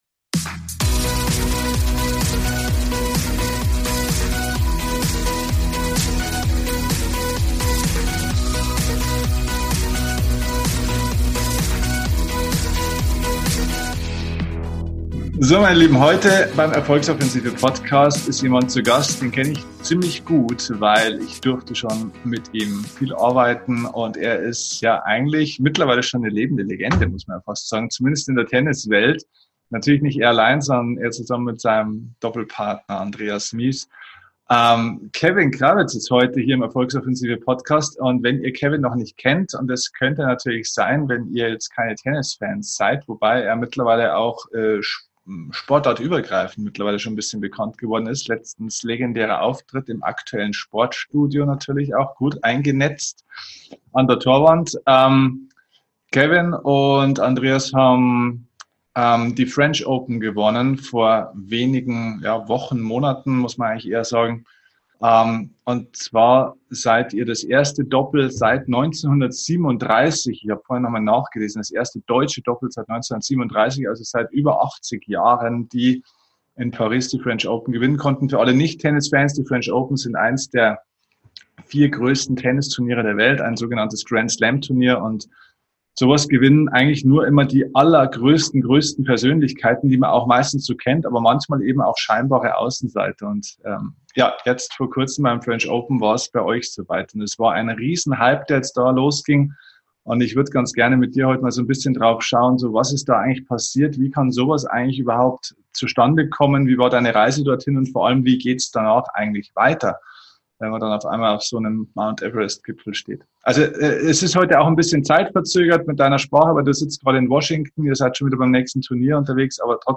#228 Der Weg zum Champion - Interview mit Tennis Grand Slam Sieger Kevin Krawietz | Mentale Vorbereitung | Rückschläge | Grand-Slam-Sieger ~ DIE KUNST ZU LEBEN - Dein Podcast für Lebensglück, moderne Spiritualität, emotionale Freiheit und berufliche Erfüllung Podcast